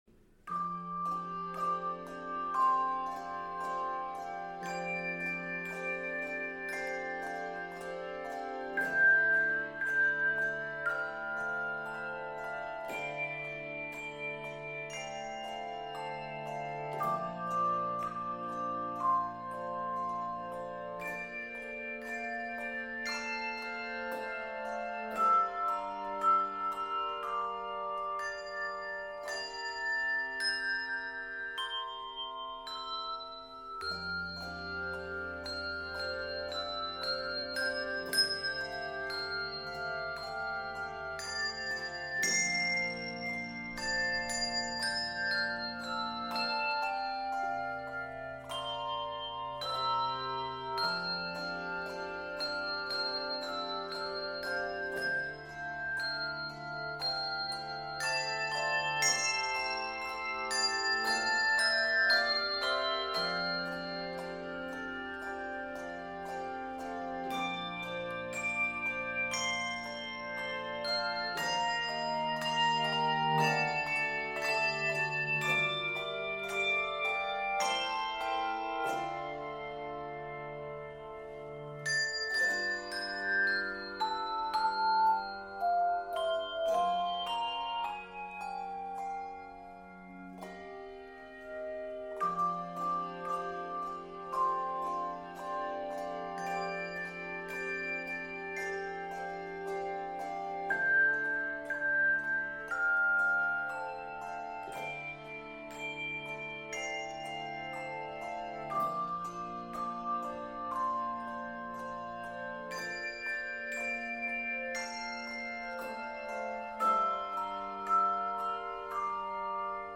Key of F Major.
Octaves: 3-6